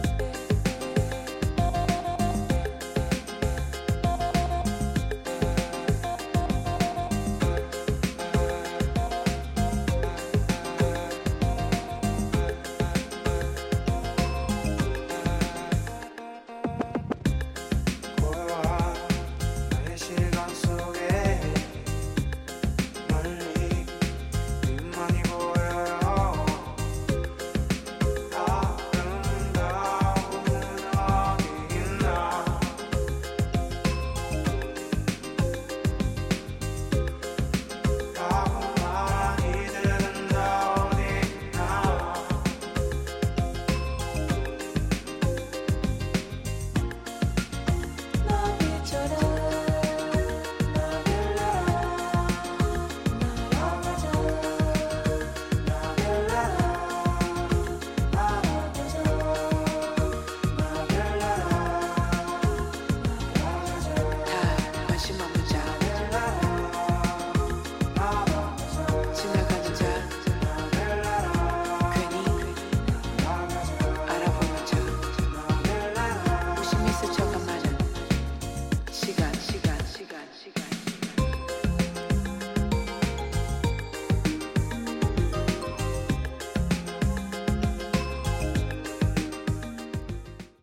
at once both nostalgic and totally modern...and more